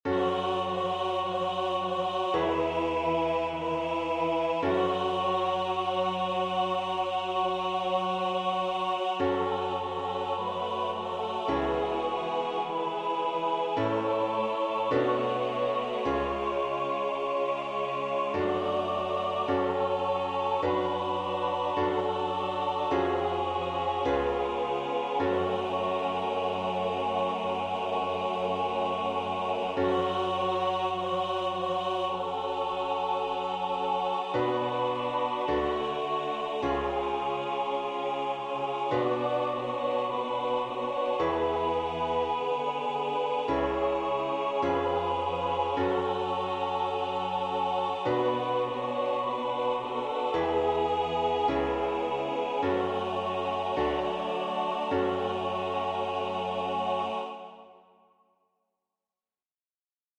• Catégorie : Chants de Carême